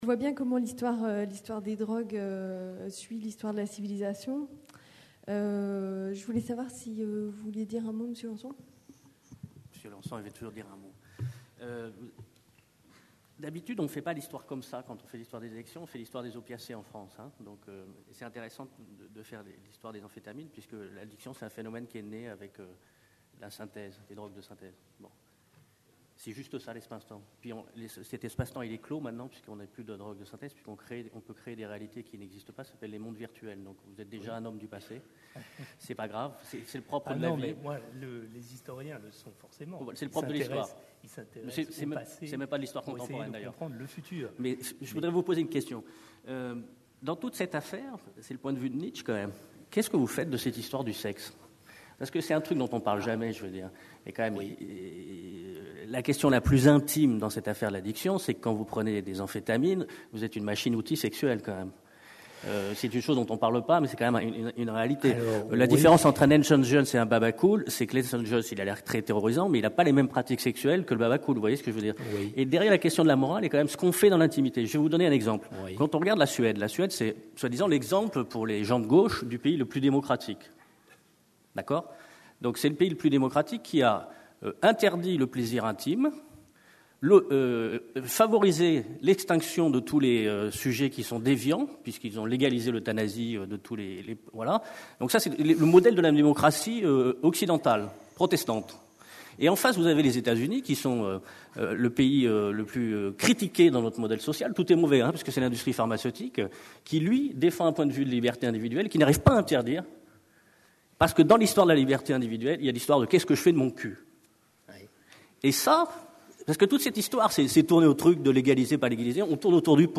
CNIPsy 2010 Marseille - Le remède et le poison : exemple des amphétamines. Débat.
CNIPsy 2010 Marseille : 7ème Congrès National des Internes en Psychiatrie (CNIPsy).